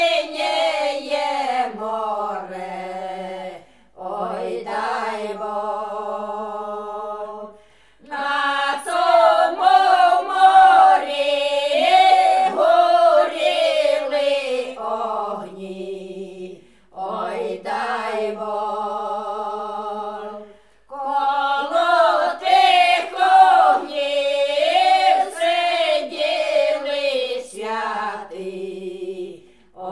Жанр: Фолк-рок
# Traditional Folk